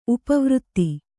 ♪ upa vřtti